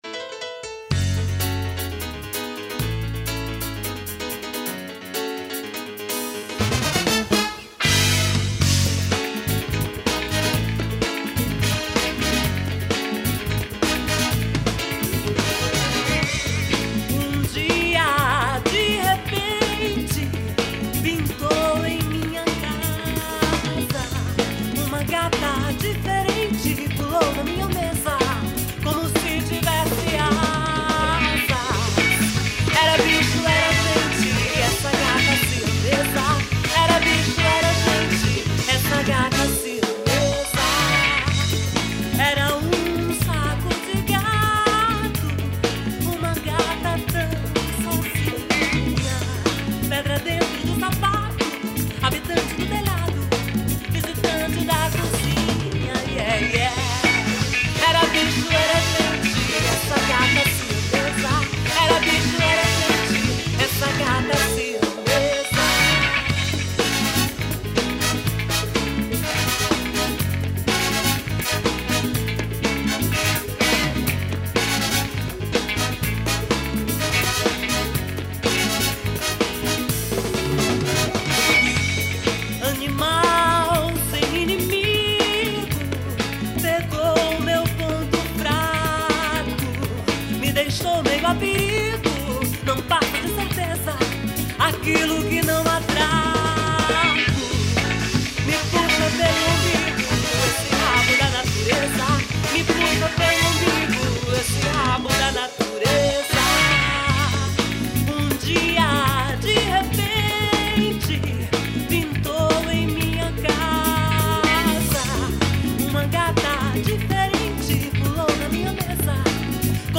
1703   02:29:00   Faixa:     Rock Nacional